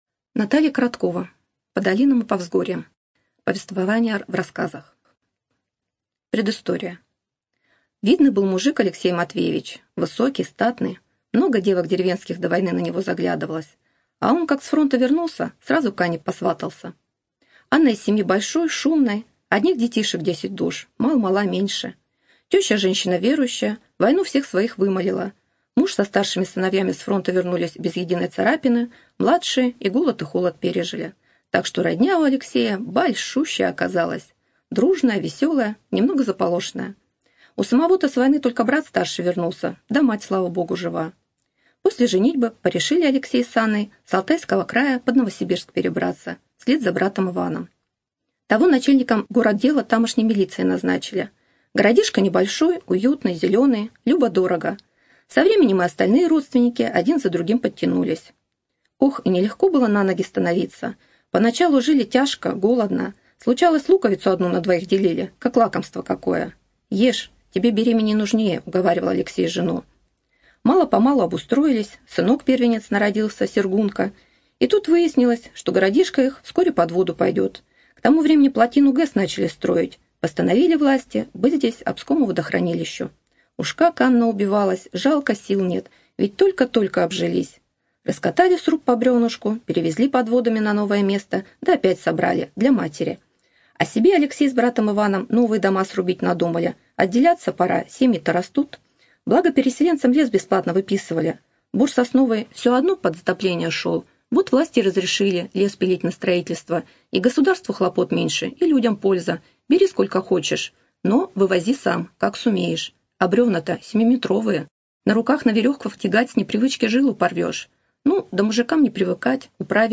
Студия звукозаписиНовосибирская областная специальная библиотека для незрячих и слабовидящих